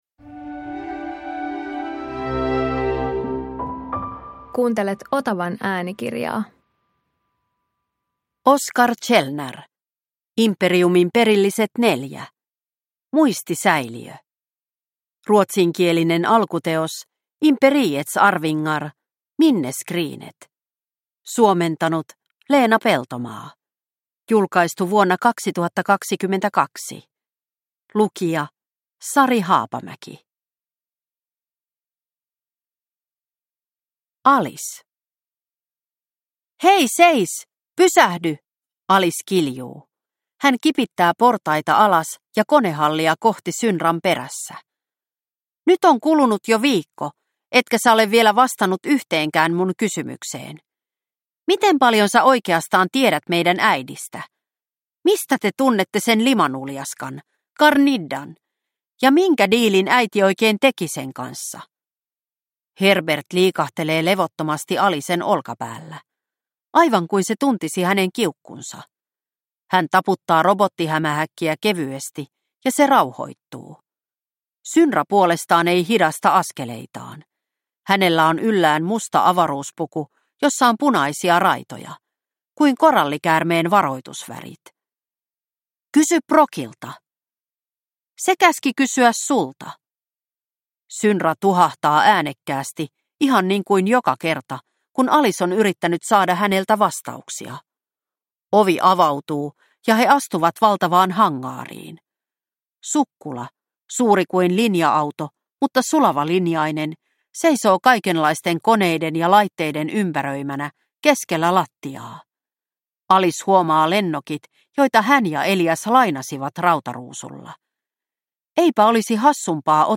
Imperiumin perilliset 4 Muistisäiliö – Ljudbok – Laddas ner